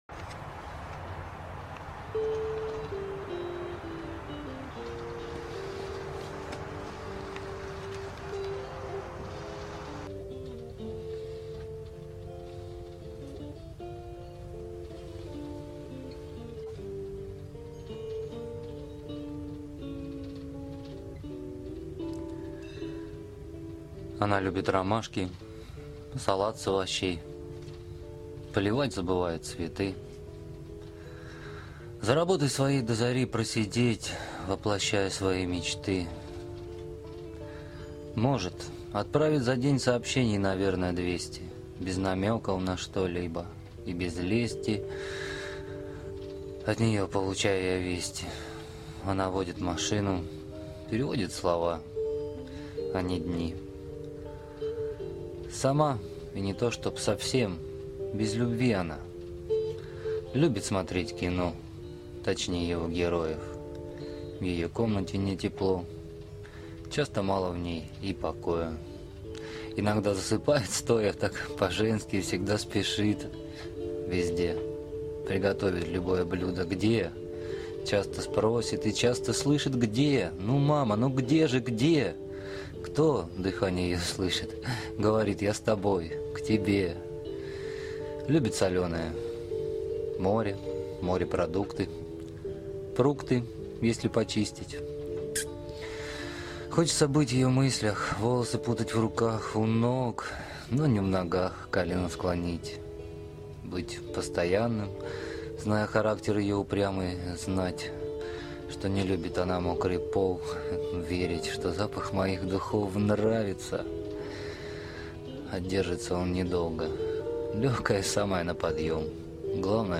Завораживающий голос и такие слова, которым веришь...